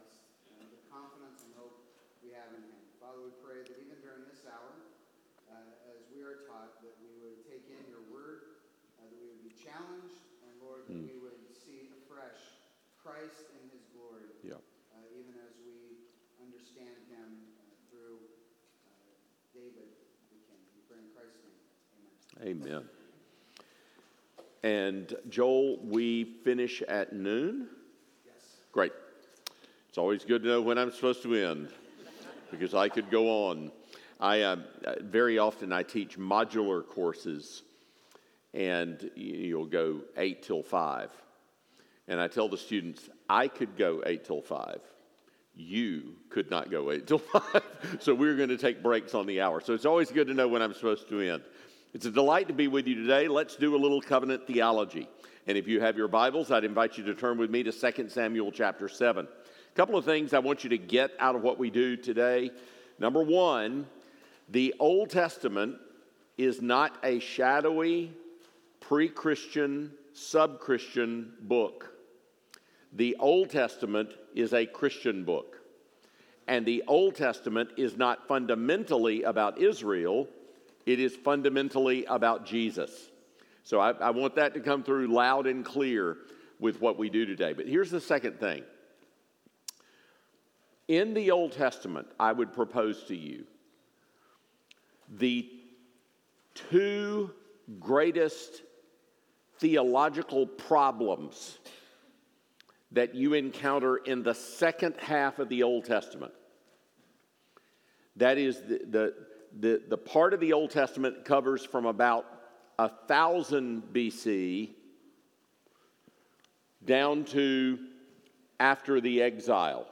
Covenant Theology: Guest Speaker